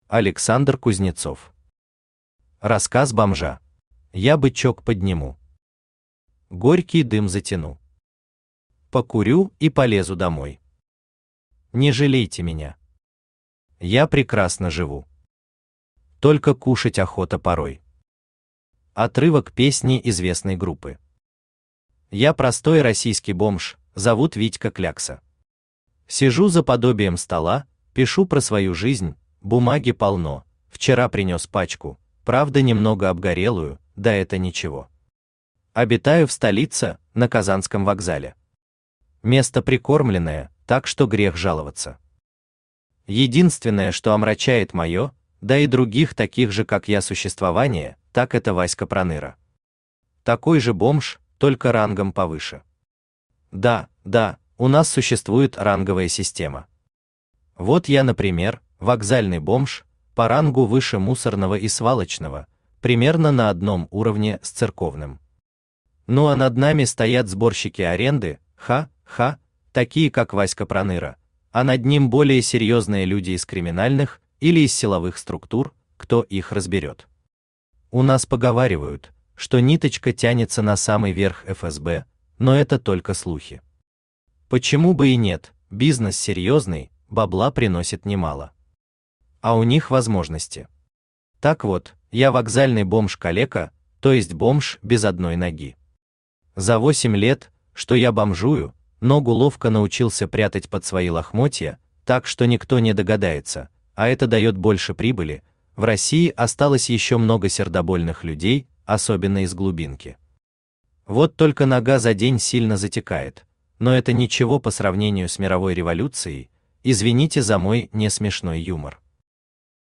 Аудиокнига Рассказ бомжа | Библиотека аудиокниг
Aудиокнига Рассказ бомжа Автор Александр Евгеньевич Кузнецов Читает аудиокнигу Авточтец ЛитРес.